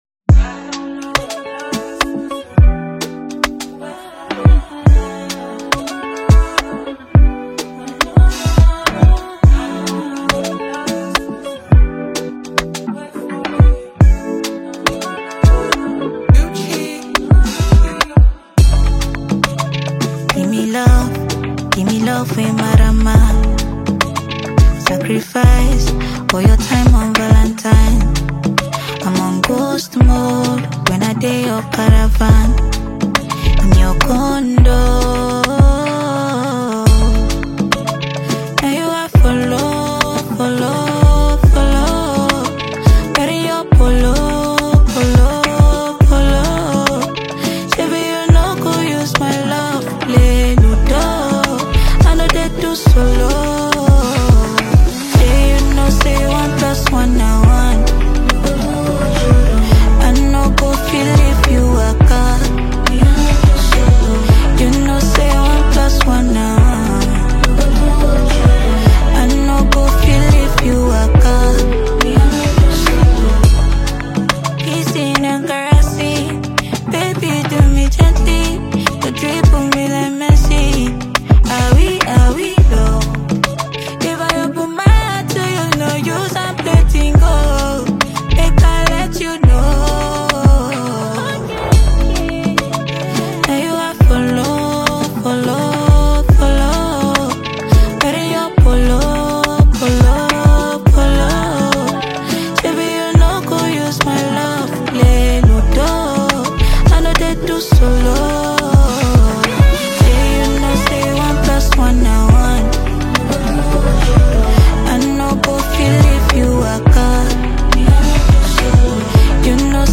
Afro-Pop single
With its catchy hooks and infectious rhythm